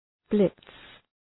Προφορά
{blıts}